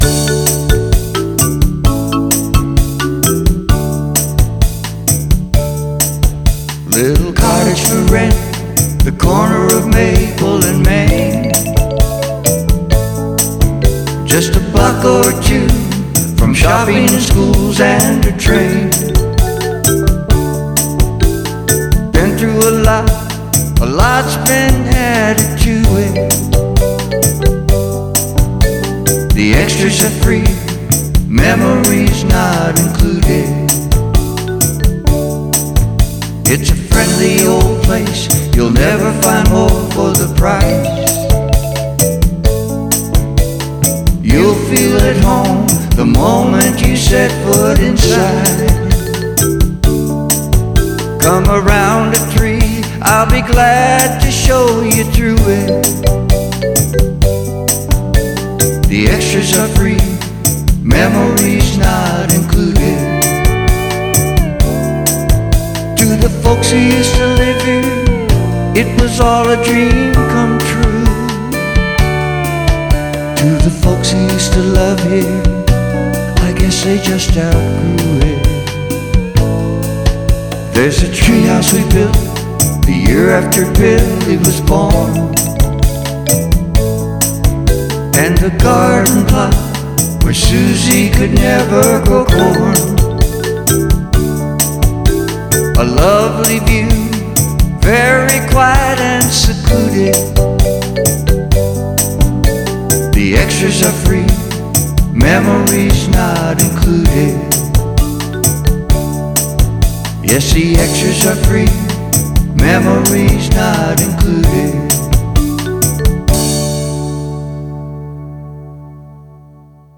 vocals, keyboards
MSA pedal steel